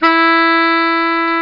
Bari Hi Sound Effect
Download a high-quality bari hi sound effect.